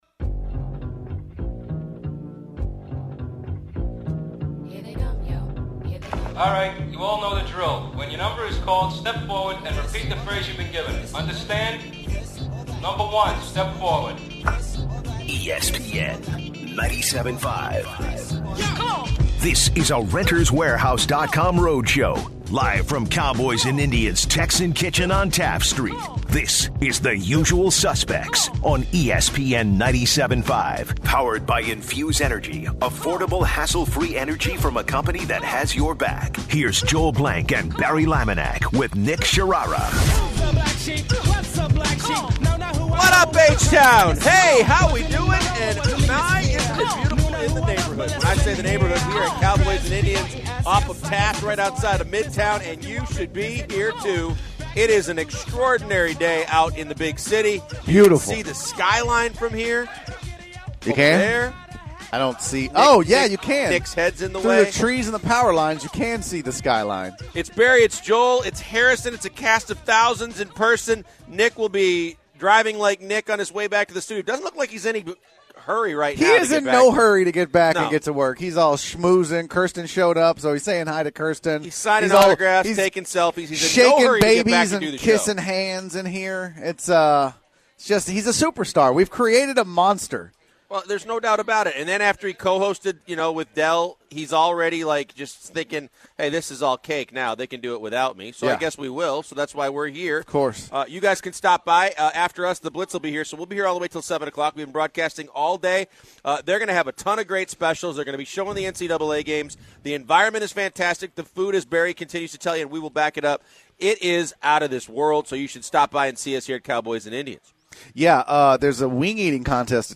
broadcast from Cowboys and Indians